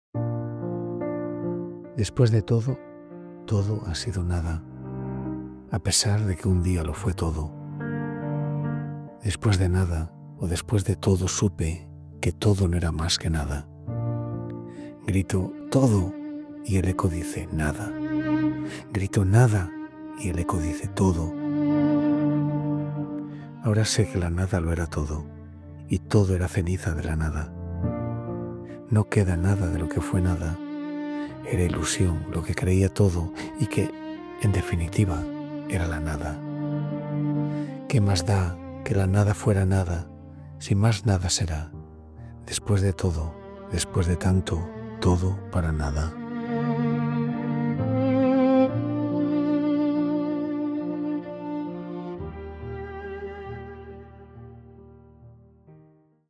Jose-Hierro.-Vida-enhanced-music.mp3